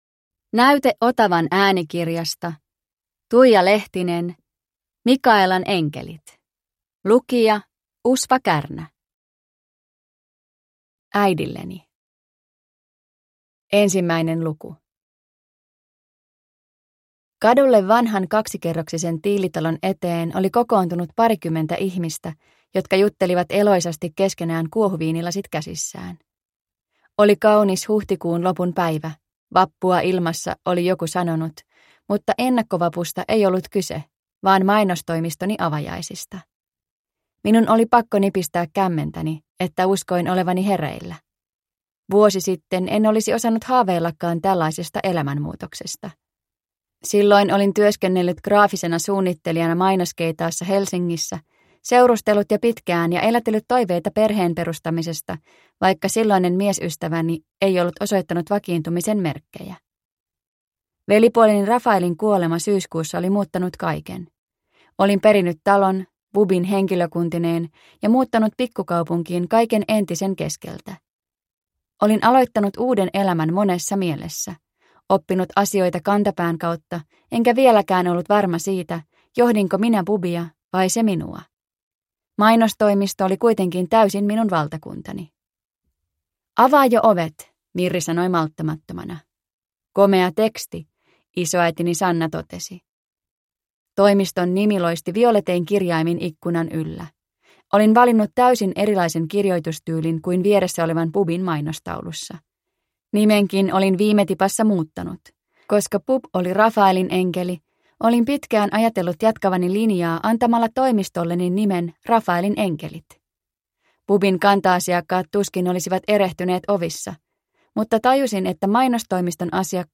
Mikaelan enkelit – Ljudbok – Laddas ner